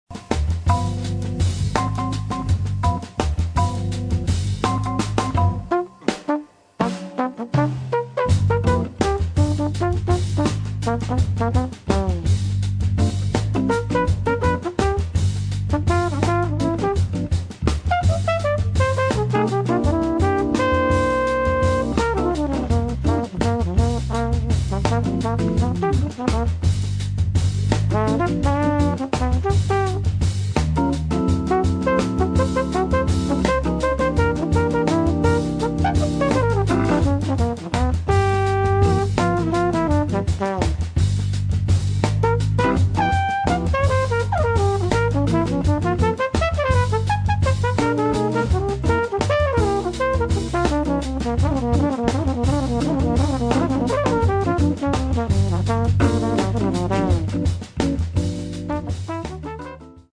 [ JAZZ ]